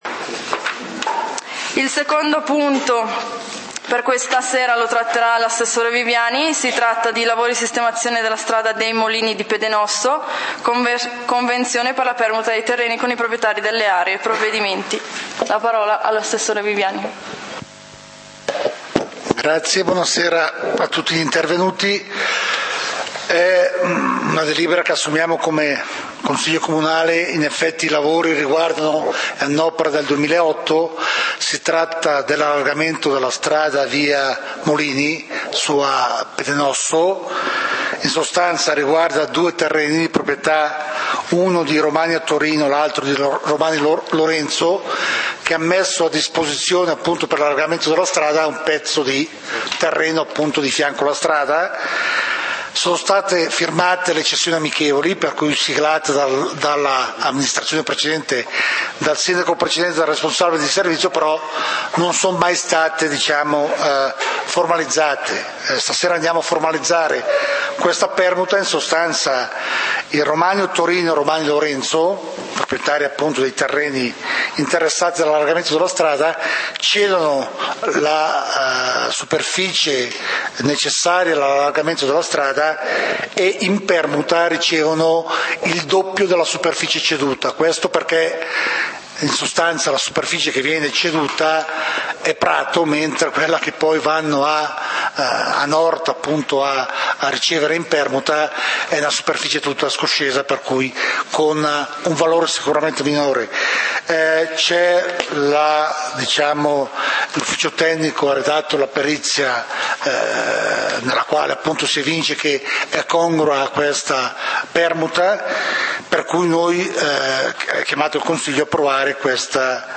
Punti del consiglio comunale di Valdidentro del 30 Novembre 2012